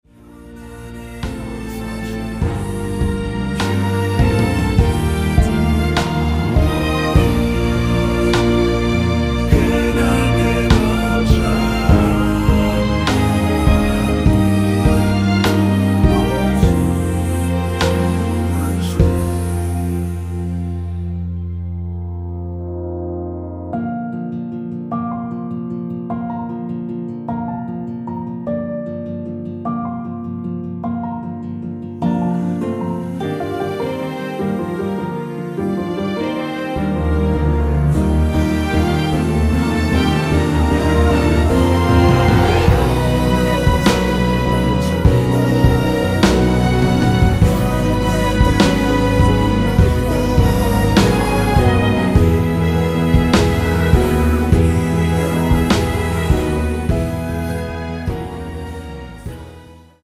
원키에서(-2)내린 코러스 포함된 MR입니다.
Bb
앞부분30초, 뒷부분30초씩 편집해서 올려 드리고 있습니다.
중간에 음이 끈어지고 다시 나오는 이유는